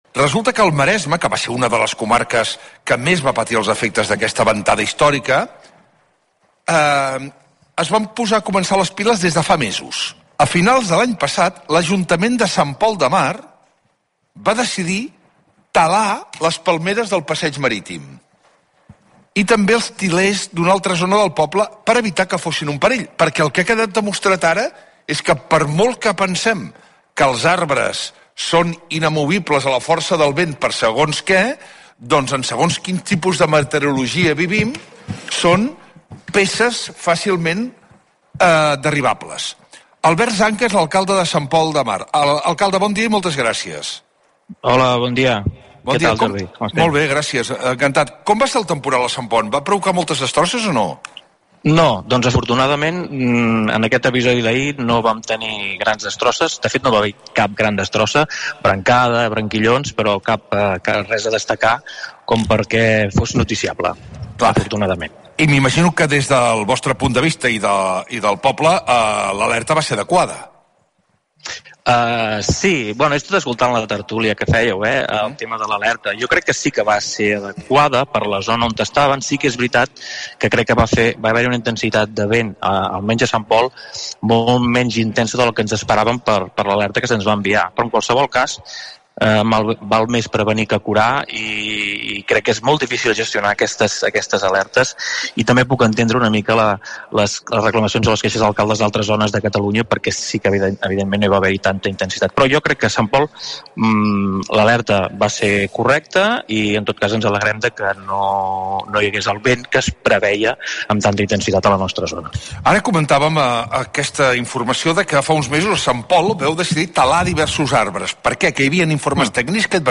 L'emissora RAC1, a través del programa El Món a RAC1 que dirigeix el periodista Jordi Basté, n'ha fet ressò aquest matí en una entrevista a l'alcalde, Albert Zanca, qui ha destacat la importància de la prevenció i la planificació per evitar incidències durant episodis meteorològics adversos.
Jordi_Baste_entrevista_Albert_Zanca.mp3